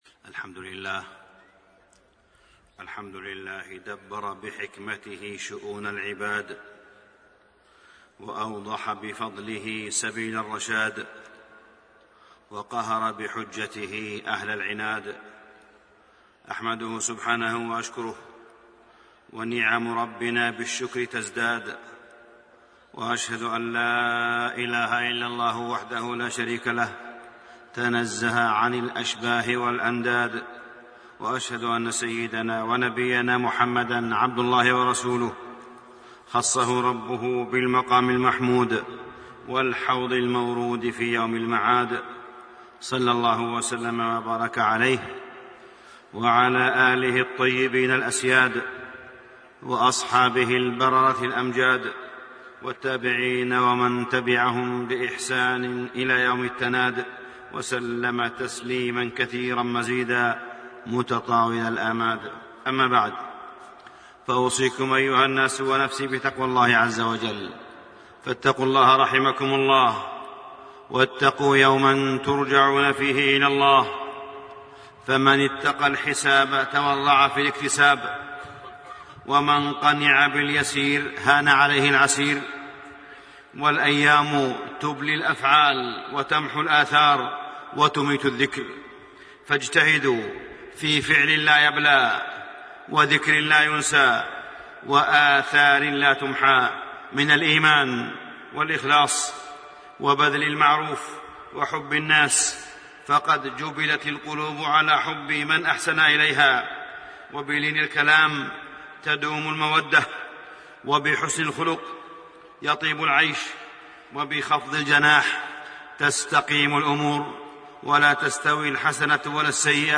تاريخ النشر ٢٦ صفر ١٤٣٣ هـ المكان: المسجد الحرام الشيخ: معالي الشيخ أ.د. صالح بن عبدالله بن حميد معالي الشيخ أ.د. صالح بن عبدالله بن حميد آداب المعاملات بين الزوجين